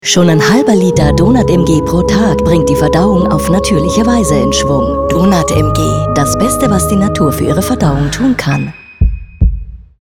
sehr vielseitige, erfahrene Theater und Filmschauspielerin deutsch österreichisches deutsch
wienerisch
Sprechprobe: Werbung (Muttersprache):
very experienced actress and voice actress stage-tv-movie-microfone